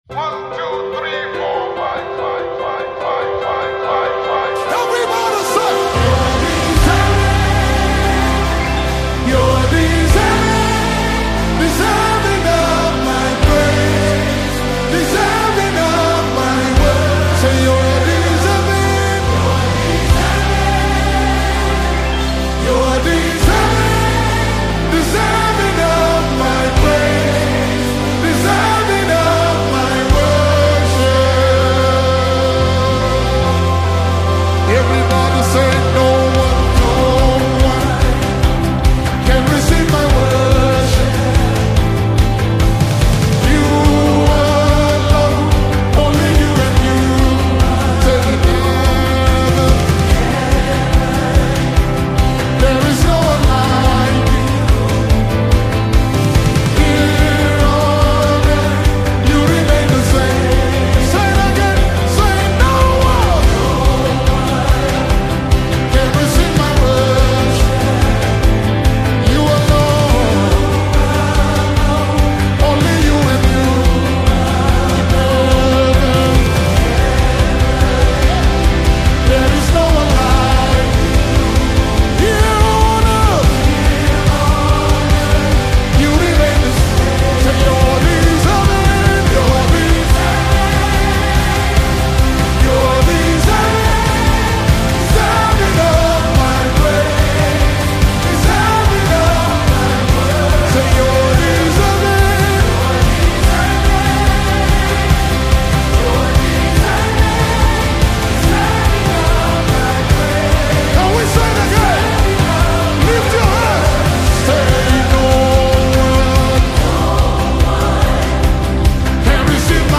gospel
worship anthem
Known for his deep spiritual sound and passion for revival
soul-stirring melody